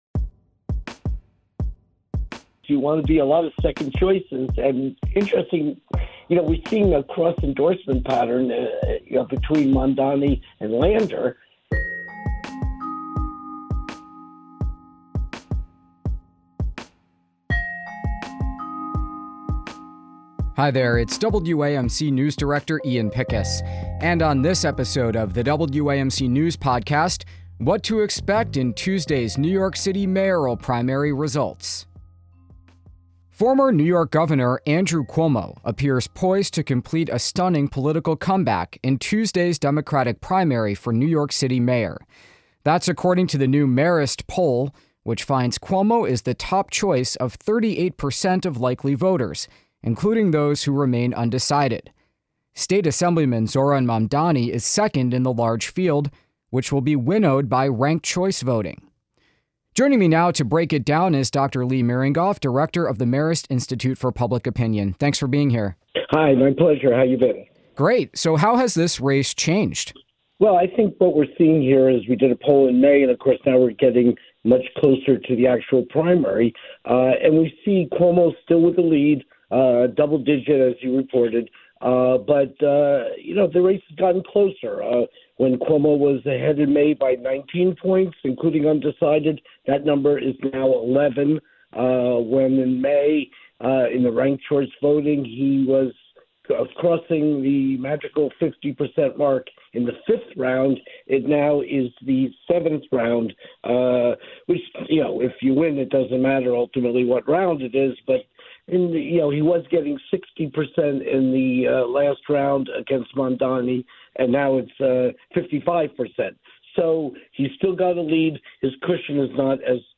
WAMC News Podcast WAMC News Podcast - Episode 614 Jun 23 2025 | 00:10:04 1x 00:00 / 00:10:04 Subscribe Share Spotify RSS Feed Share Link Embed